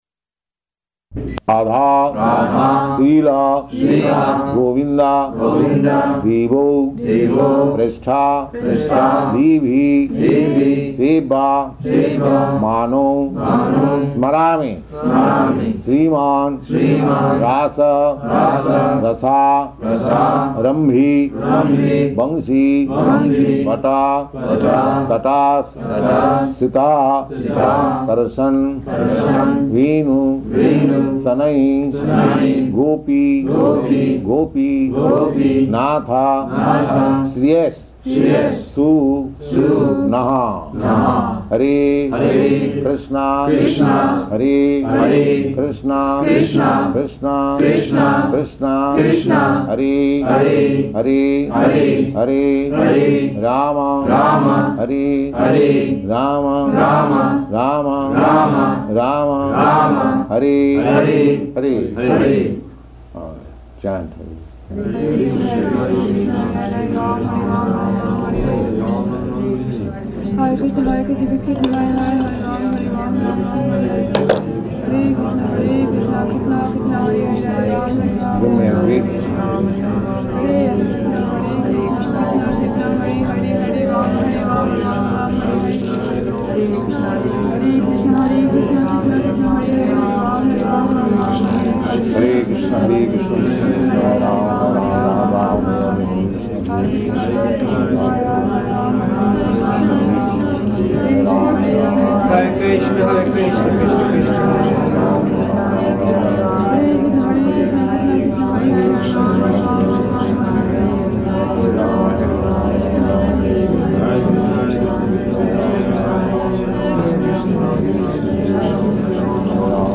-- Type: Initiation Dated: May 4th 1969 Location: Boston Audio file